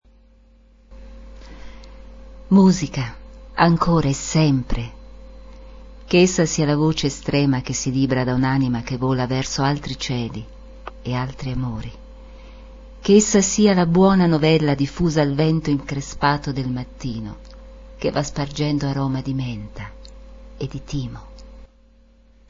Concerti Estivi dell'
Teatro Stabile "Vittorio Emanuele II"